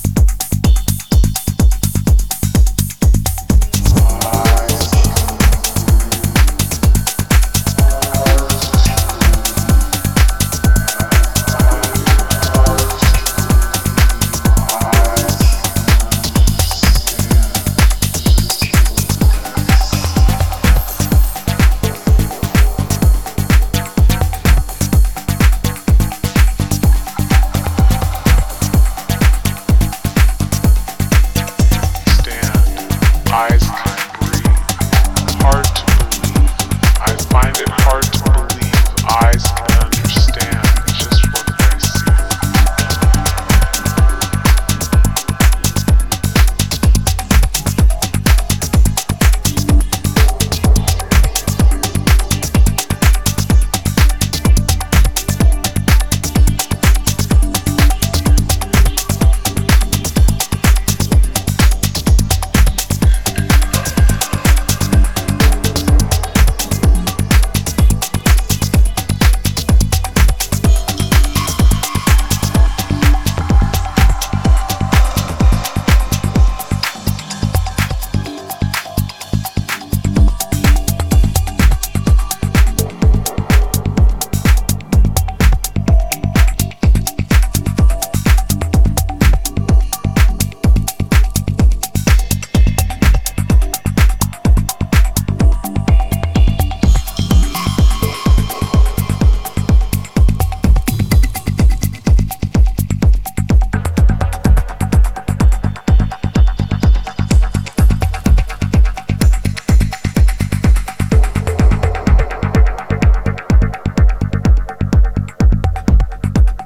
4 intricate signals for late-night movement.